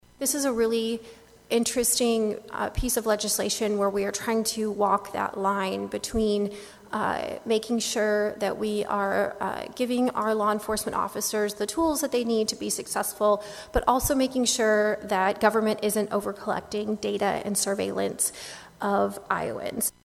REPRESENTATIVE MEGAN JONES FROM SIOUX RAPIDS, SAYS THE IMAGES COULD ONLY BE STORED IN IOWA FOR 30 DAYS IF THE BILL BECOMES LAW.